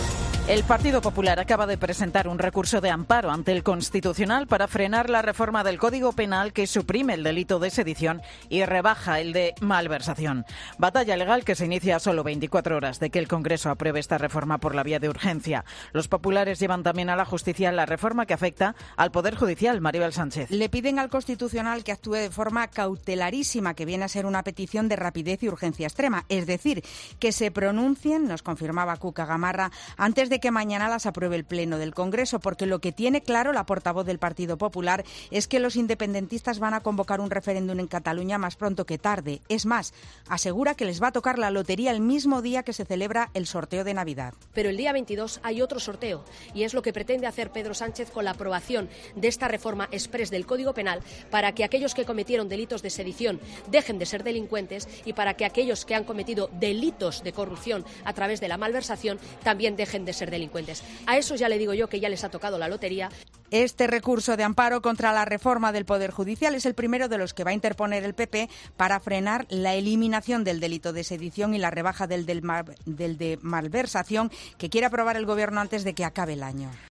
El PP presenta recurso de amparo ante el TC por la reforma del Código Penal. Crónica